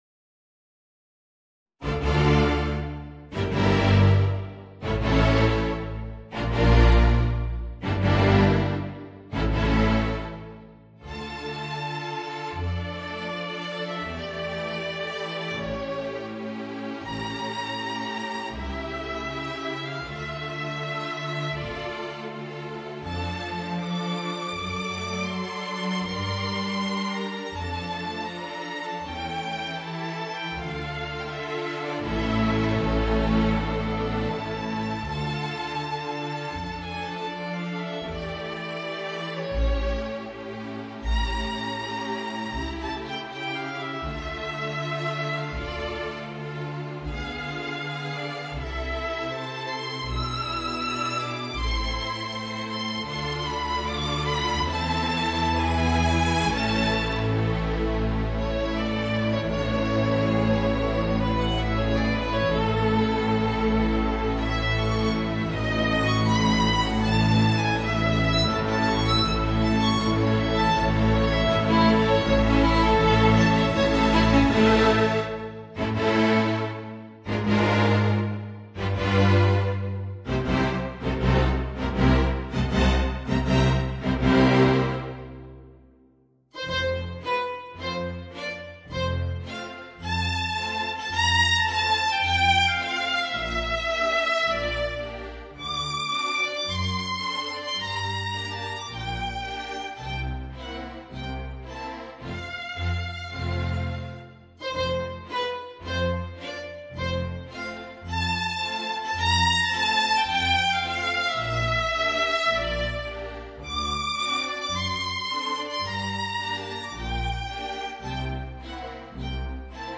Voicing: Flute and String Quintet